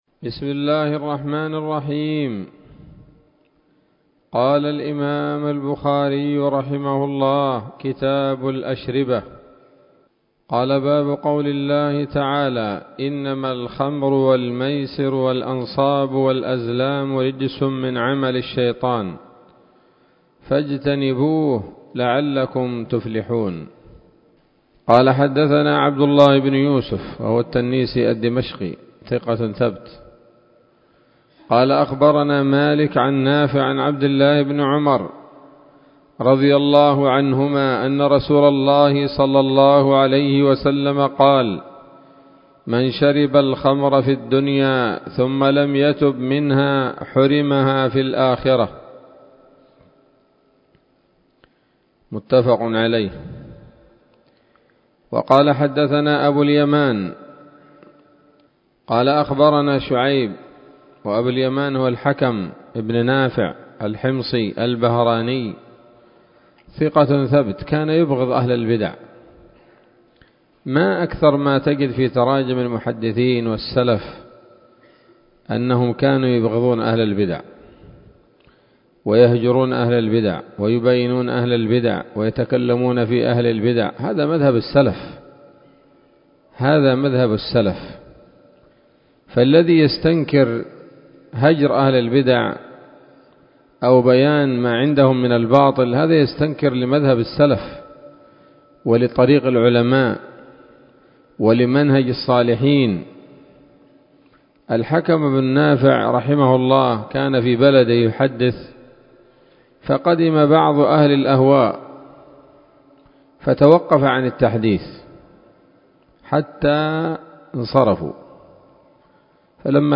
الدرس الأول من كتاب الشربة من صحيح الإمام البخاري